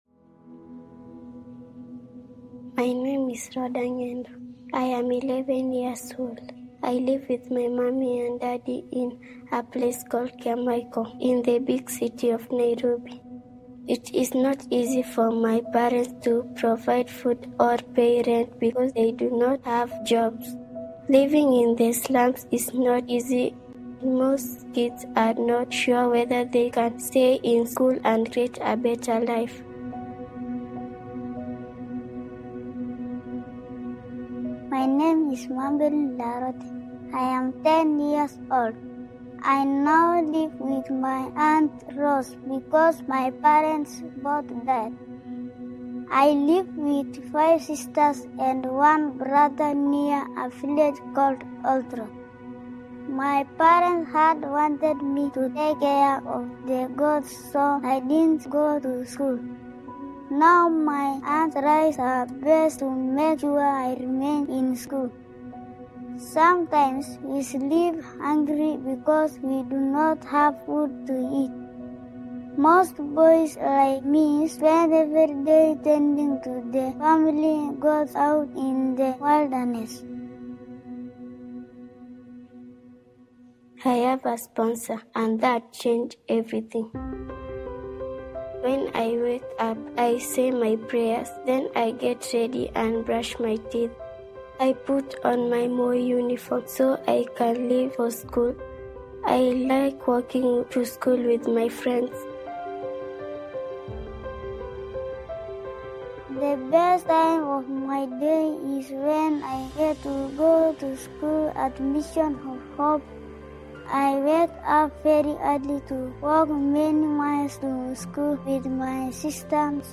A collection of ACC Sunday Messages that are not a part of a Sermon Series